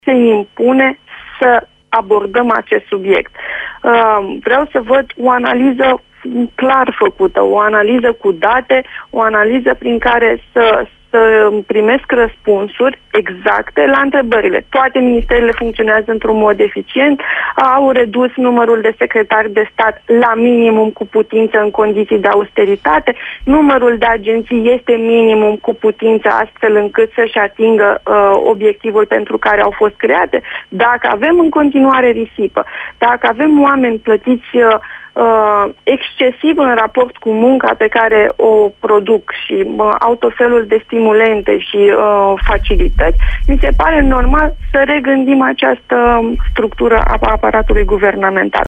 Parlamentarii PDL care nu sustin pachetul de masuri anticriza al Guvernului nu sunt fortati sa ramana in partid. O spune la RFI vicepresedintele formatiunii, Raluca Turcan.